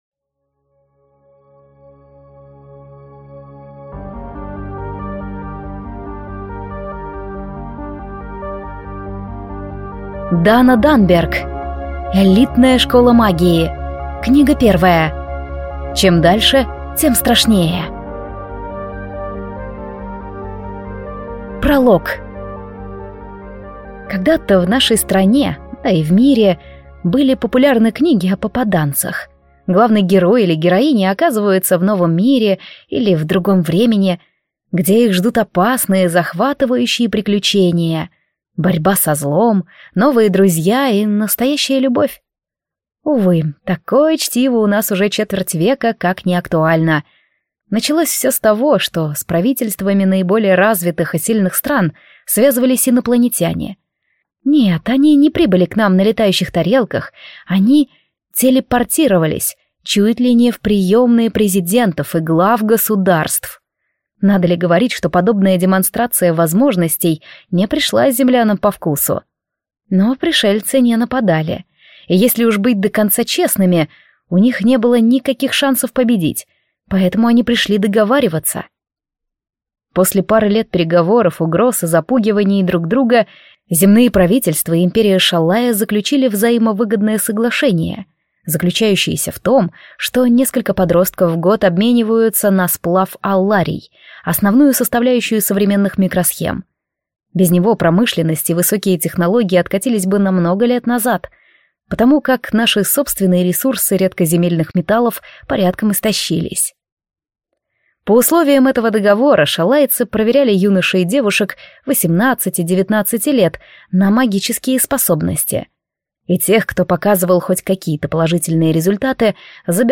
Аудиокнига Элитная школа магии. Чем дальше, тем страшнее…